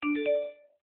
sms-received.fea8d6d.ogg